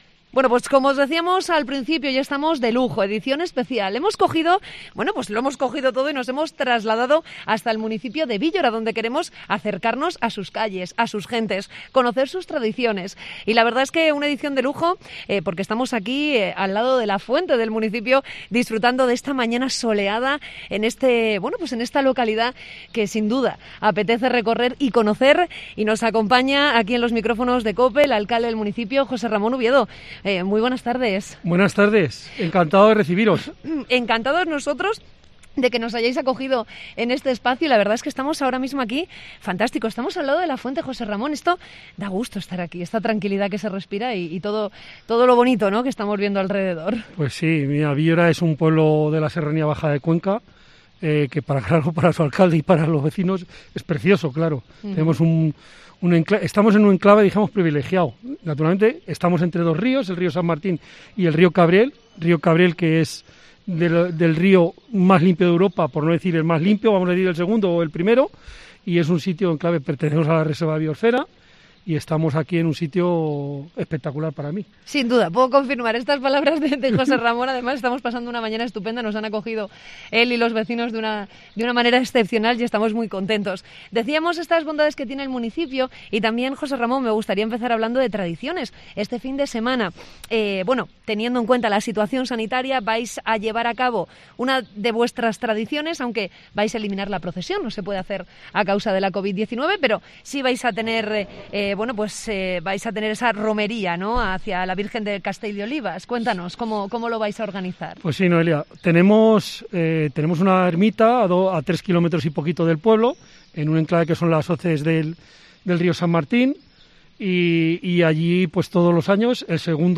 Entrevista con el alcalde de Víllora, José Ramón Ubiedo